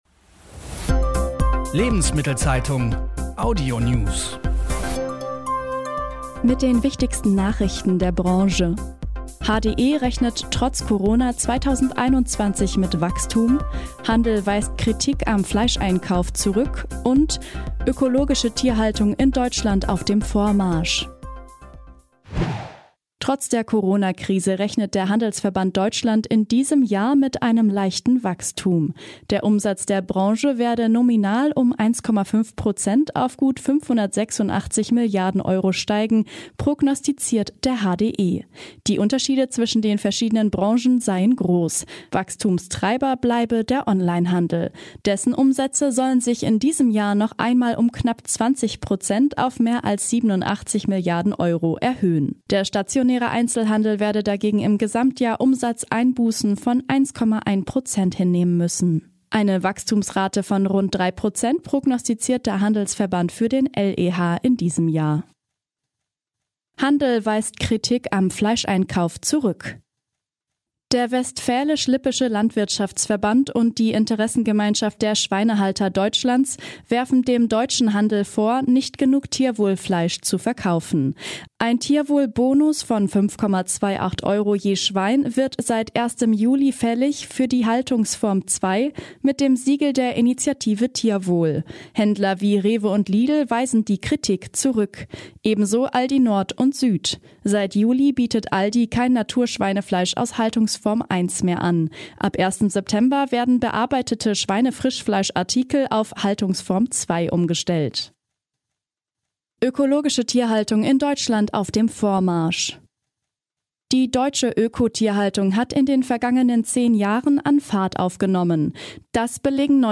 Die wichtigsten Nachrichten aus Handel und Konsumgüterwirtschaft zum Hören